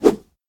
fishpole.ogg